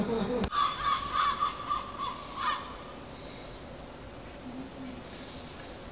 Observação BirdNET - Gaivota-de-asa-escura - 2022-04-14 17:50:34
Gaivota-de-asa-escura observado com o BirdNET app. 2022-04-14 17:50:34 em Lisboa